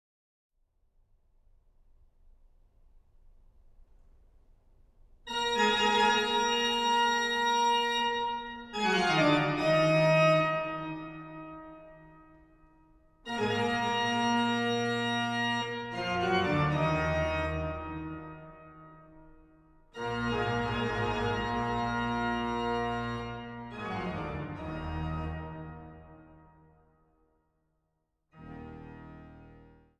an der Großen Silbermann-Orgel im Freiberger Dom
Orgel